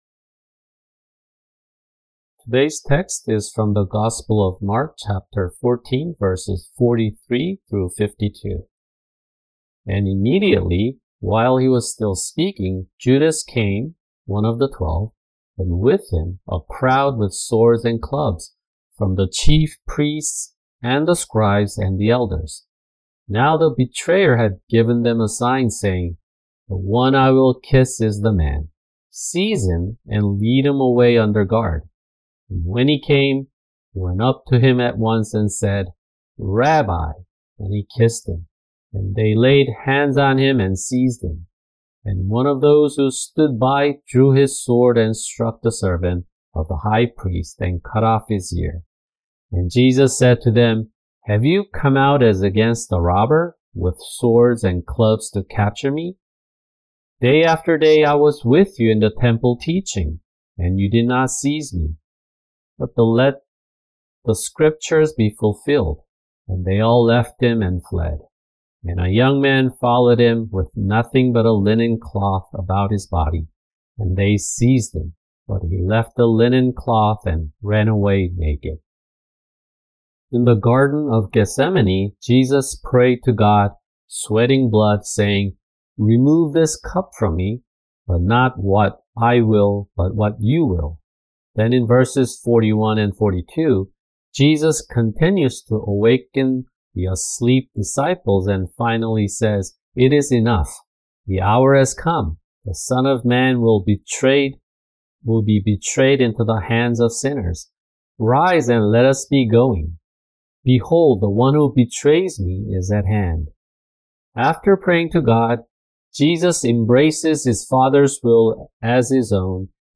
[주일 설교] 마가복음(69) 14:43-52